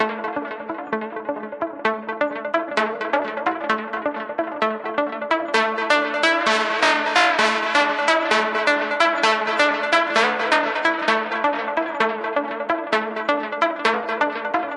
Tag: 150 bpm Trap Loops Synth Loops 5.11 MB wav Key : Unknown